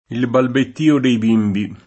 balbettio [ balbett & o ] s. m.